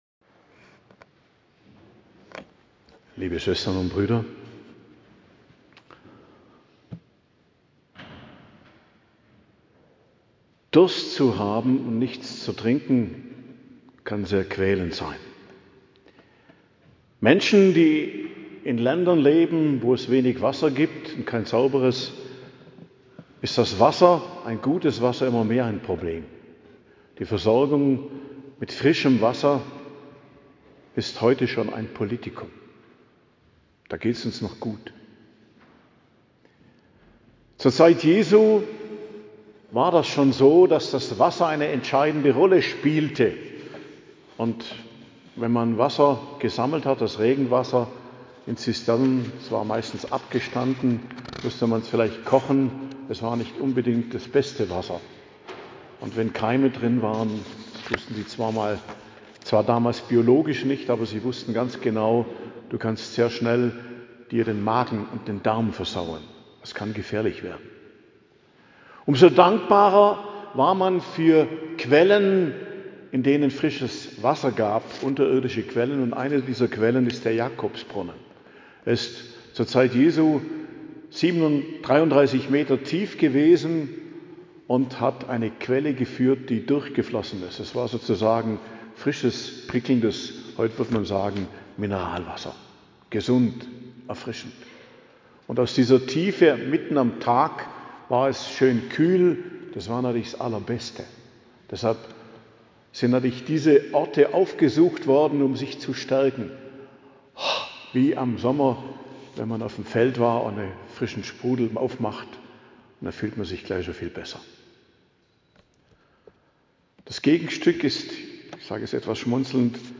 Predigt zum 3. Fastensonntag, 8.03.2026 ~ Geistliches Zentrum Kloster Heiligkreuztal Podcast